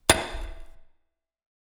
ajout des samples de pioche
pickaxe_4.wav